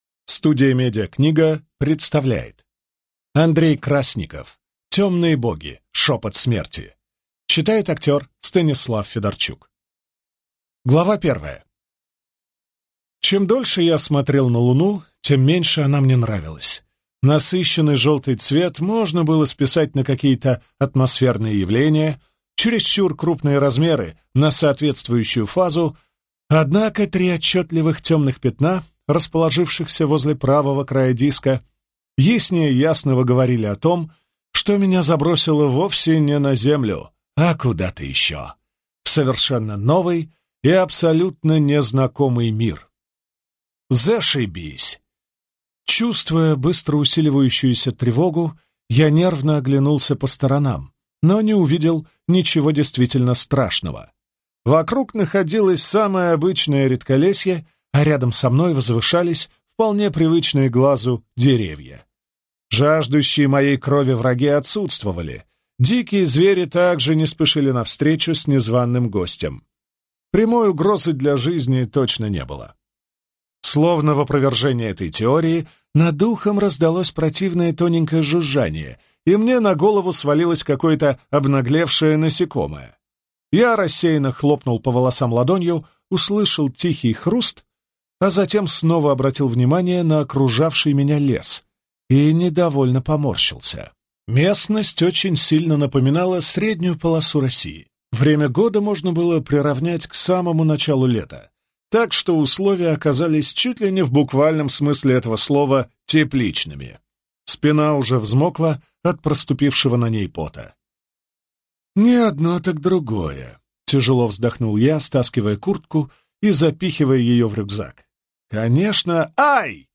Аудиокнига Темные боги. Шепот смерти | Библиотека аудиокниг